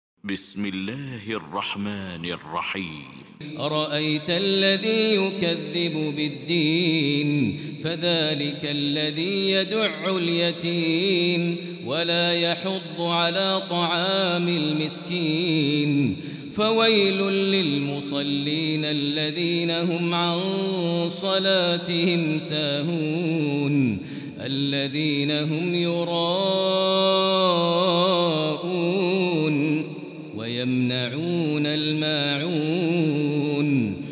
المكان: المسجد الحرام الشيخ: فضيلة الشيخ ماهر المعيقلي فضيلة الشيخ ماهر المعيقلي الماعون